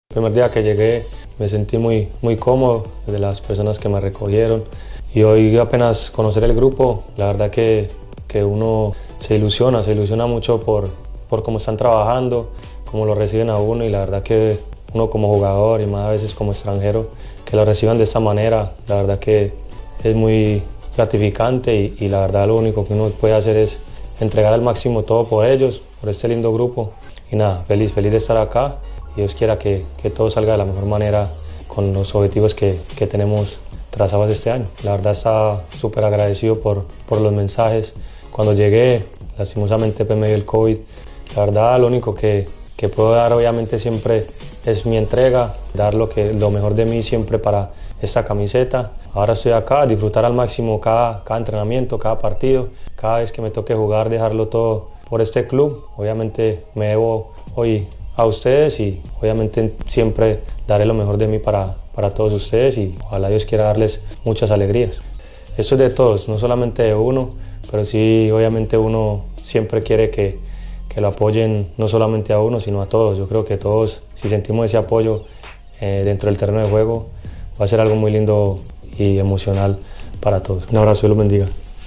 (Edwin Cardona, volante colombiano de Racing)
El colombiano, en entrevista con la web del club, aseguró que su compromiso hoy es con los hinchas y el plantel, que buscará su título 19 en Liga.